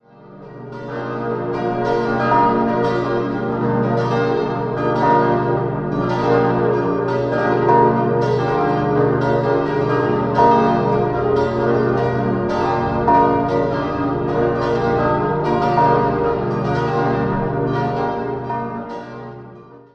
6-stimmiges erweitertes Salve-Regina-Geläute: b°-d'-f'-g'-b'-d'' Die große Glocke wurde 1865 von Johannes Grassmayr gegossen, die fünf kleineren Glocken stammen ebenfalls aus der Gießerei Grassmayr aus dem Jahr 1950.